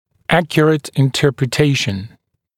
[‘ækjərət ɪnˌtɜːprɪ’teɪʃn][‘экйэрэт инˌтё:при’тэйшн]правильная интерпретация